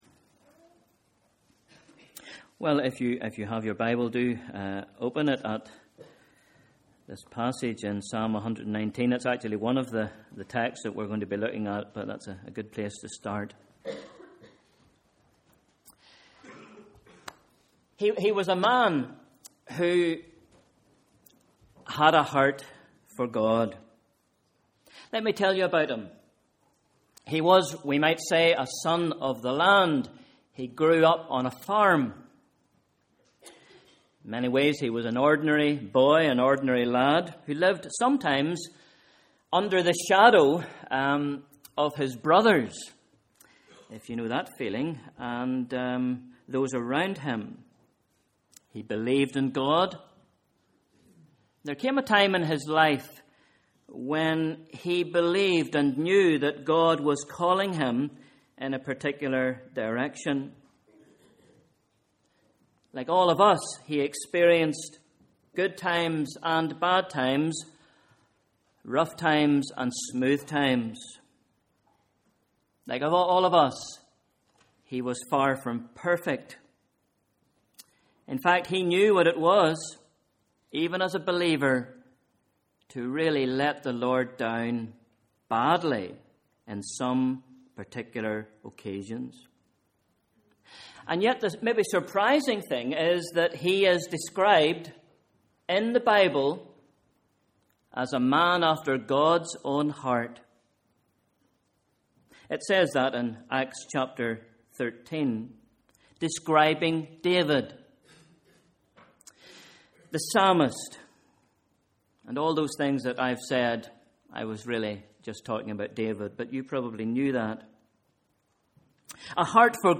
Sunday 24th February 2013: Morning Service